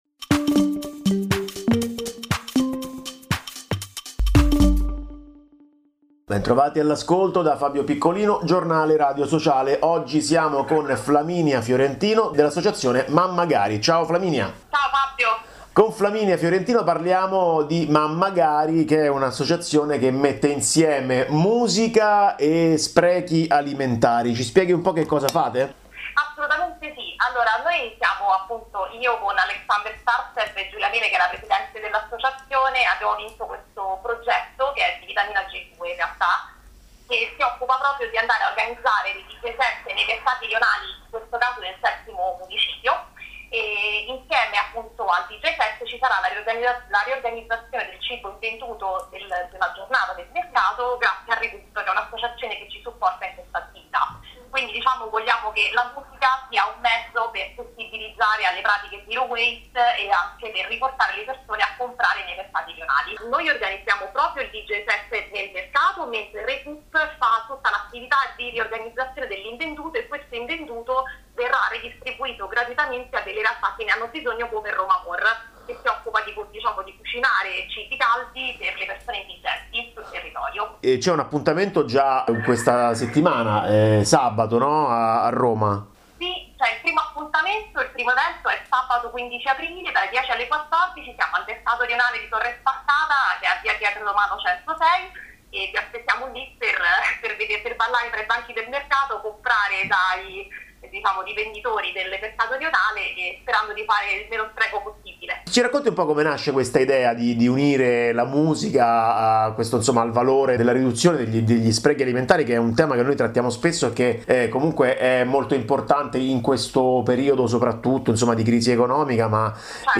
Musica contro lo spreco di cibo. Intervista